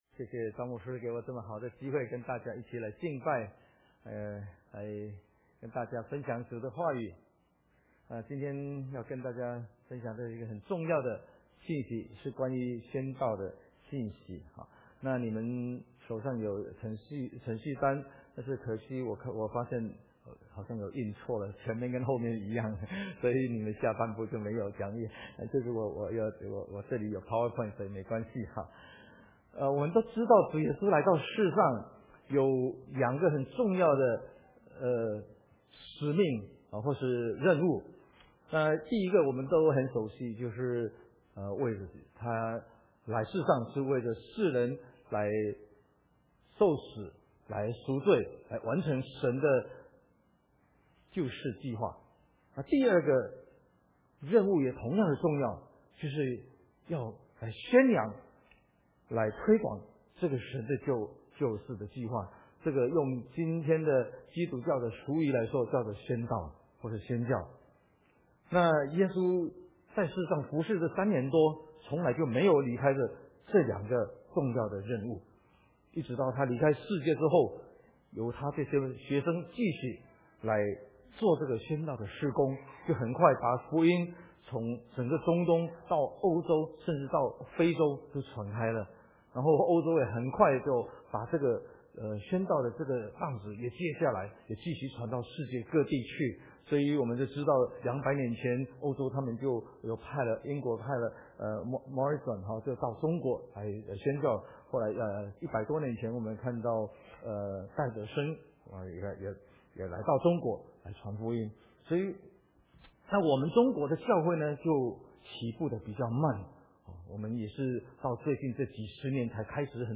Mandarin Sermons – Page 87 – 安城华人基督教会